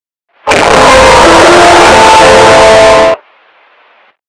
EFEITO SONOROS SBT ESTOURADO - Botão de Efeito Sonoro